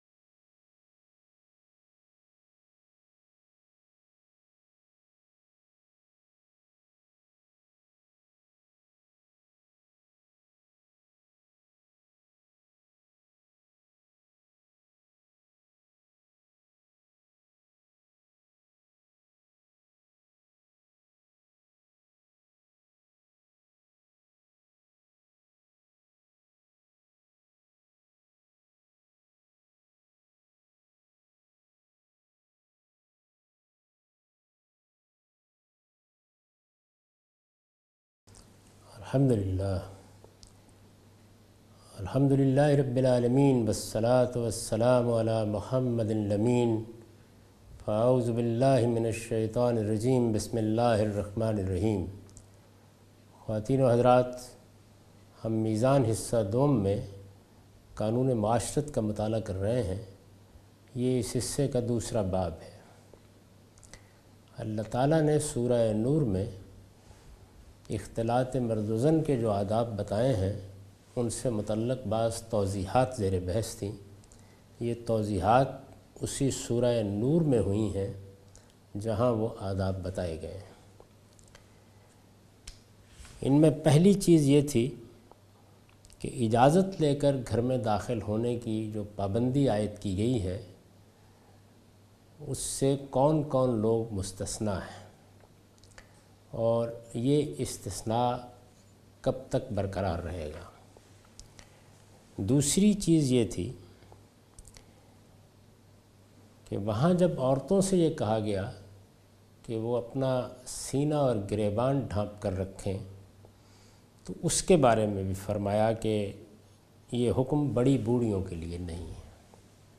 A comprehensive course on Islam, wherein Javed Ahmad Ghamidi teaches his book ‘Meezan’.
In this lecture he teaches norms of gender interaction in Islam.